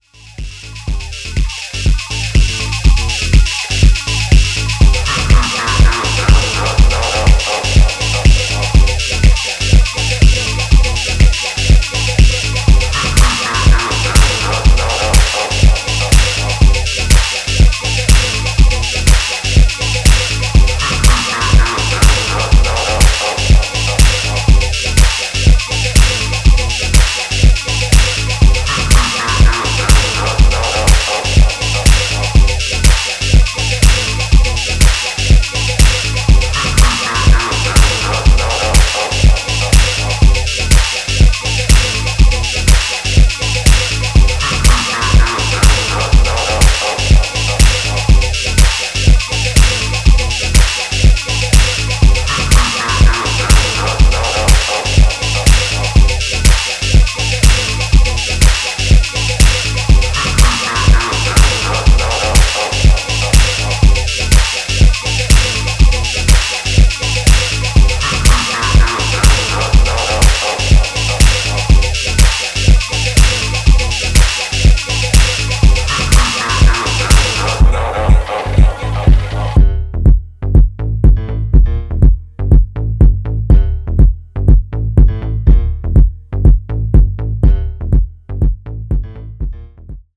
ヒプノティックなローファイ・シカゴ